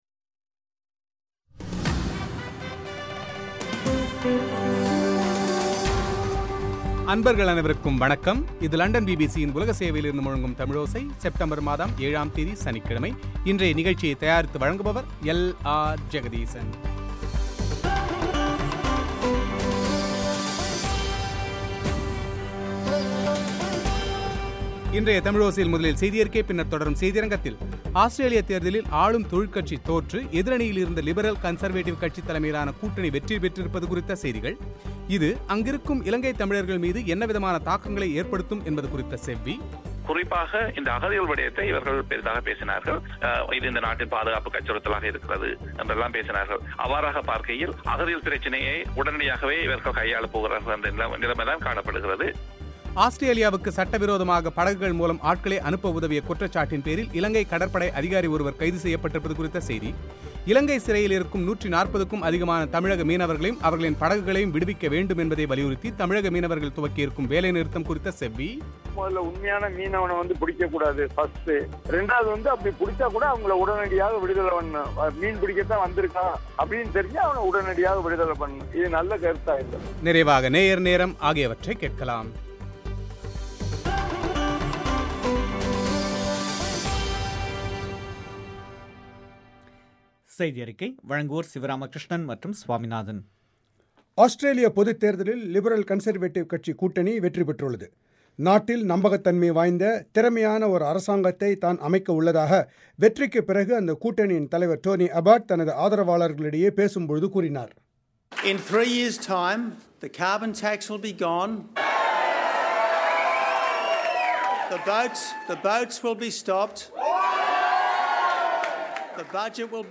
இது அங்குள்ள இலங்கை தமிழர்கள் மீது என்னவிதமான தாக்கங்களை ஏற்படுத்தும் என்பது குறித்த செவ்வி;